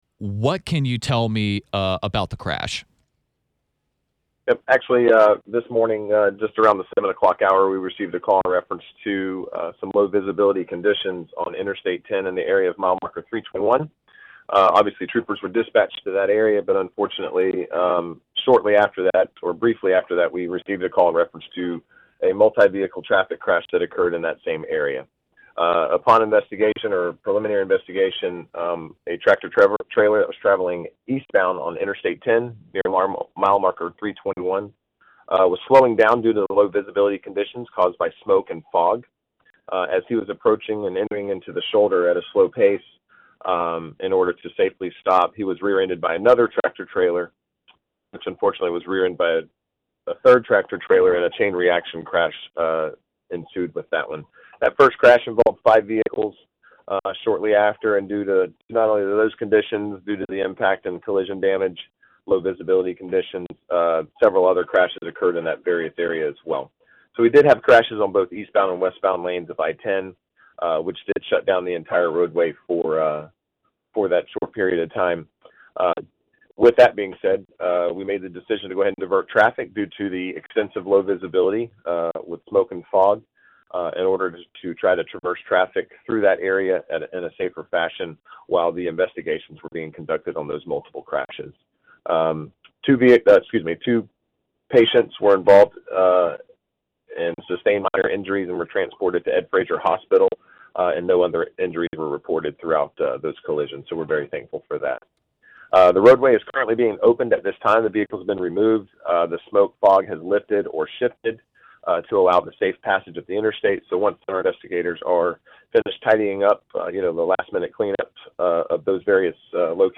the full interview.